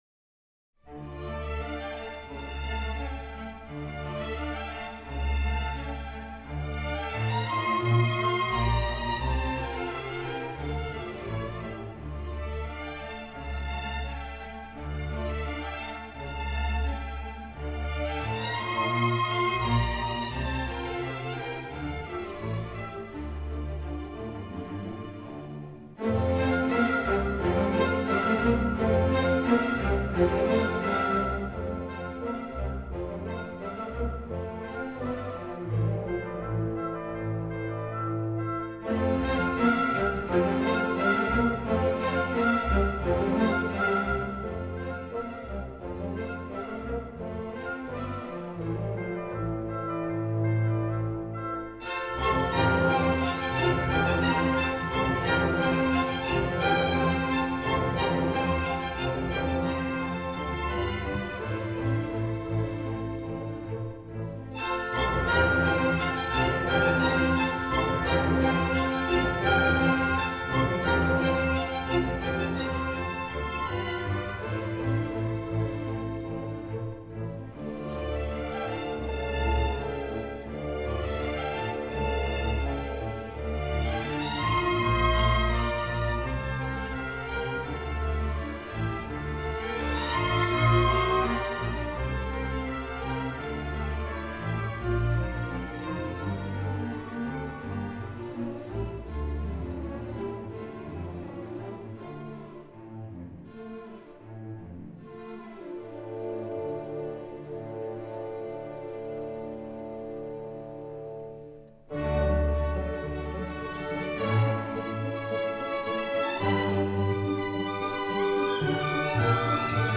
02 Bohemian Suite Polka